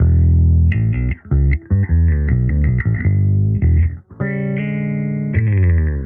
Index of /musicradar/sampled-funk-soul-samples/79bpm/Bass
SSF_JBassProc1_79E.wav